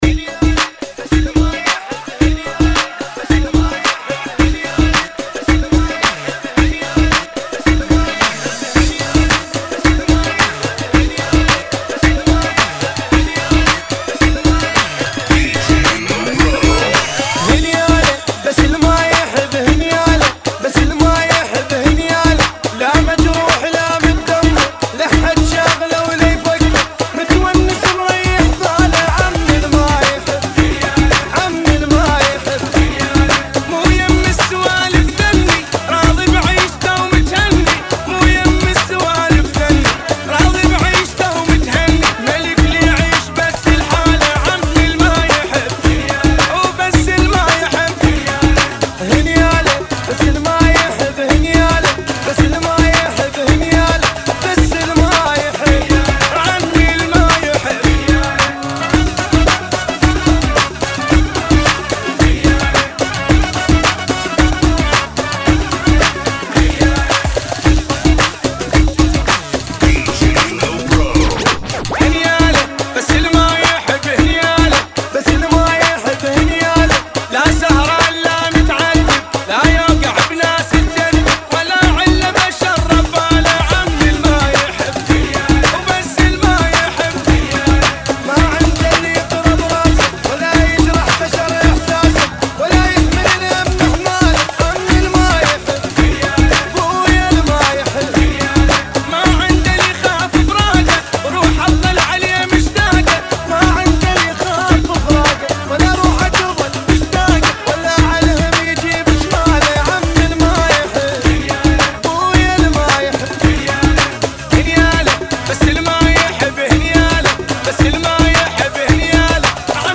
[ 110 BPM ]